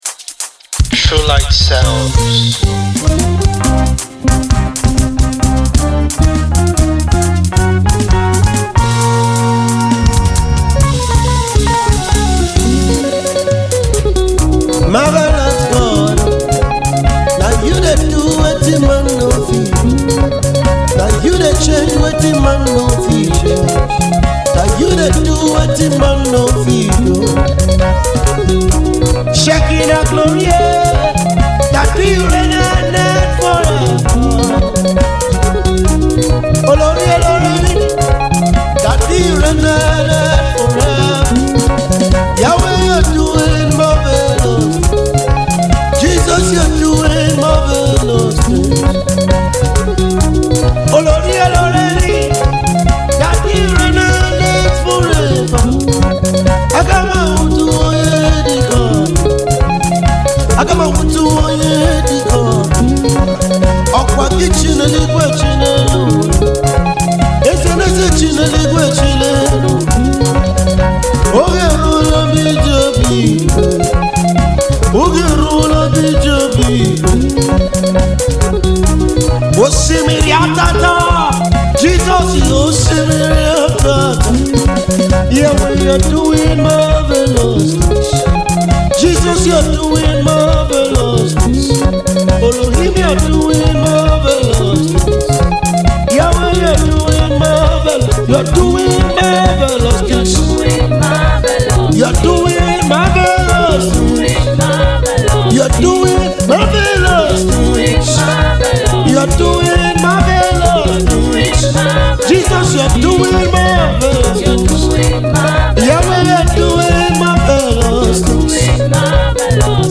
The powerful new worship single